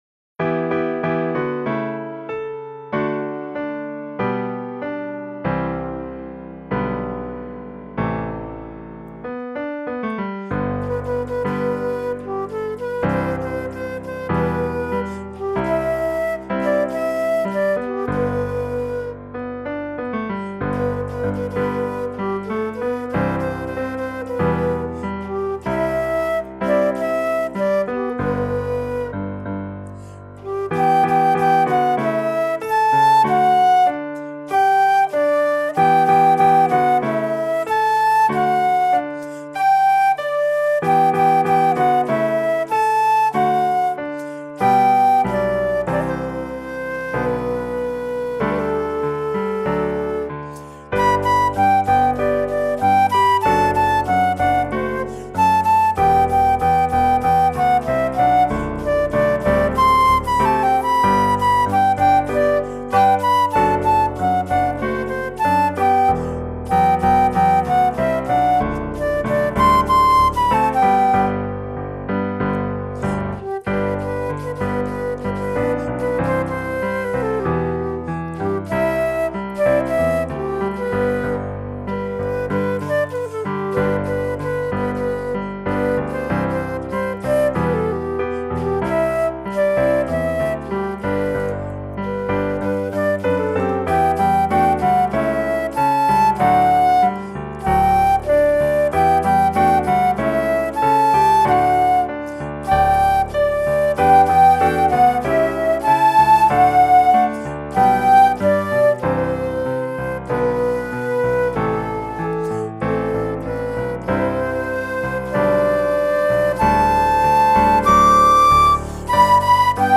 Hello, This was my first recording of my flute and piano.